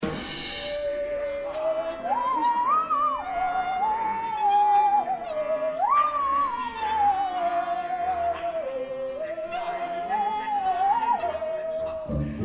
Newspaper office with Musical saw
The Musical saw sounds "singing", and therefore it can imitate a singer or put a grotesque note on the singing voice.
It goes unisono with the voice and - if played with Musical saw- gives a malicious character to the voice (listen to 1a.).